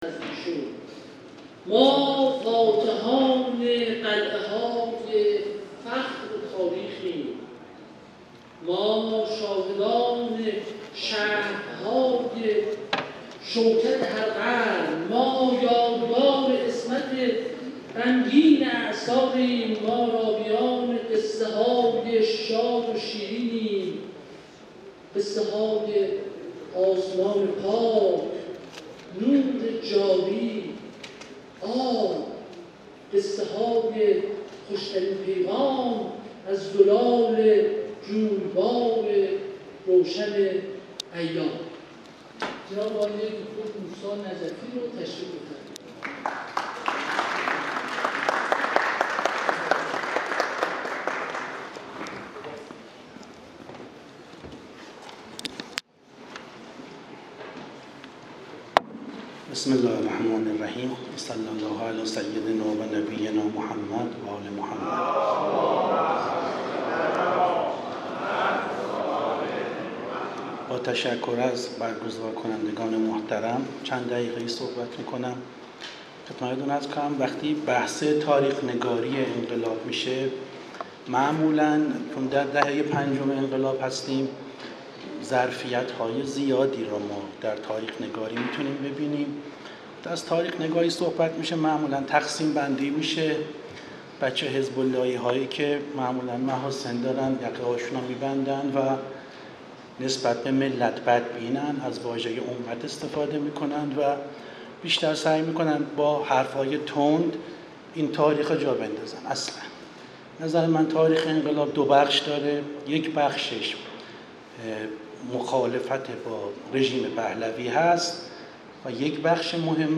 سومین همایش بزرگداشت روز تاریخ‌نگاری انقلاب اسلامی، همراه با تجلیل از تاریخ‌نگاران برگزیده، بیست و نهم بهمن‌ماه 403 در کتابخانه، موزه و مرکز اسناد مجلس شورای اسلامی برگزار شد.
سخنرانی در همایش بزرگداشت روز تاریخ‌نگاری انقلاب اسلامی